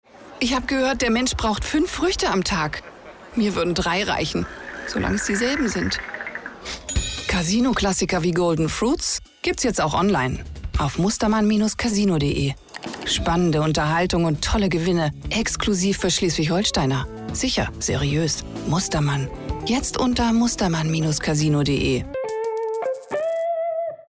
Commercial (Werbung)
Norddeutsch